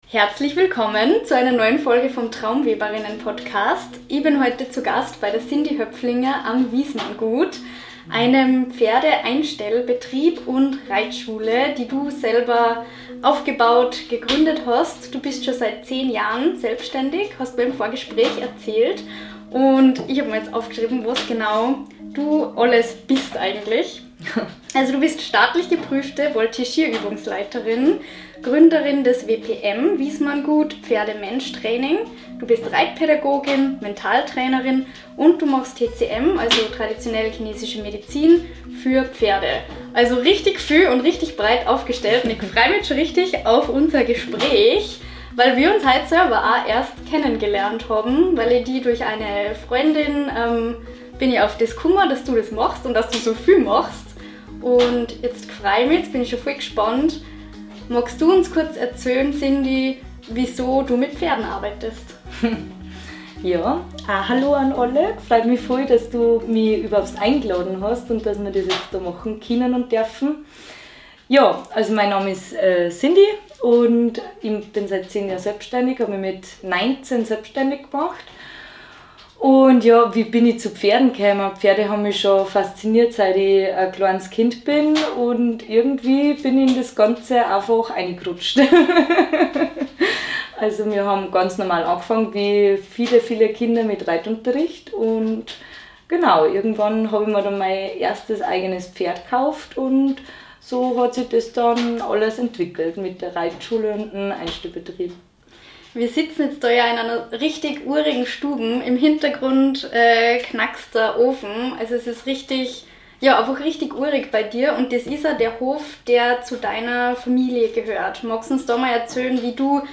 Wie ich Angst und Unsicherheit überwinde - Interview